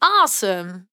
8 bits Elements
Voices Expressions Demo
Awesome.wav